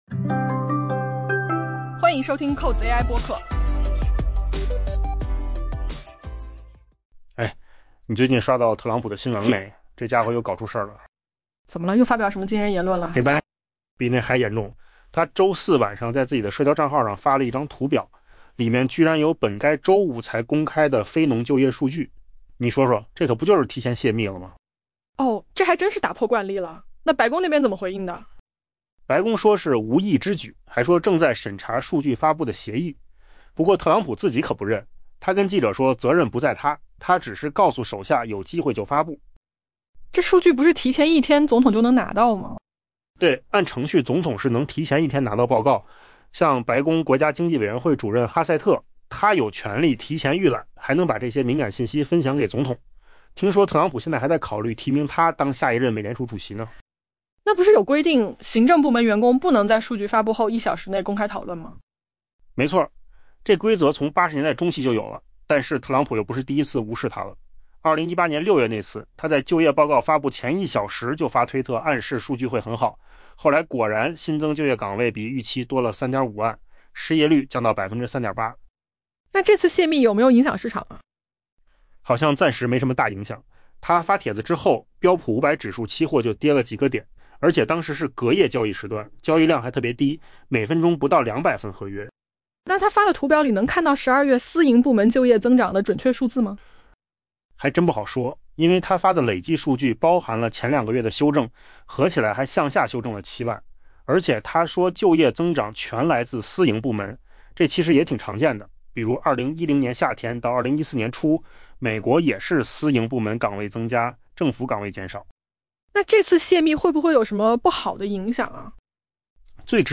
AI 播客：换个方式听新闻 下载 mp3 音频由扣子空间生成 美国总统特朗普周四晚间在其社交媒体账户上发布了一张图表，其中包含了本应在周五才公开发布的非农就业市场数据。